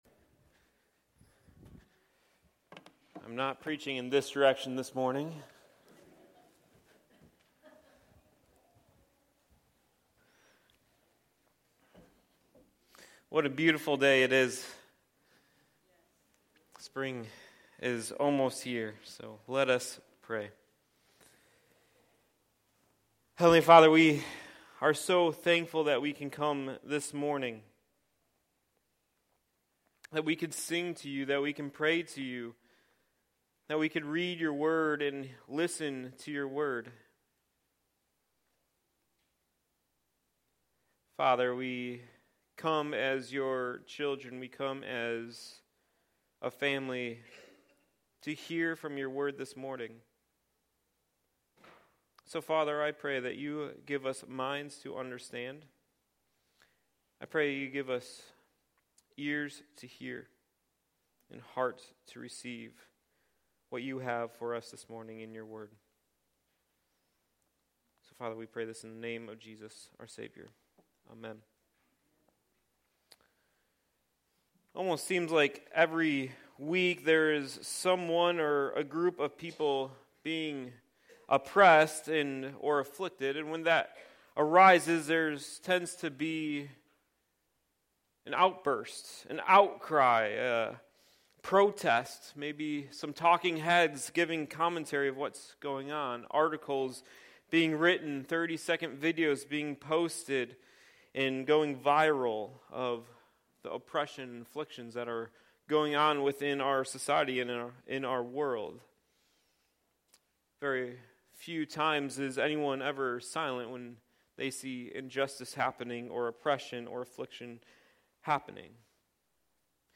Sermons | Meadows Christian Fellowship